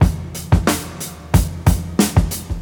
• 121 Bpm Old School Rock Breakbeat Sample F Key.wav
Free drum beat - kick tuned to the F note. Loudest frequency: 2964Hz
121-bpm-old-school-rock-breakbeat-sample-f-key-Xw8.wav